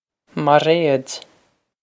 Mairéad Mar-ayd
Mar-ayd
This is an approximate phonetic pronunciation of the phrase.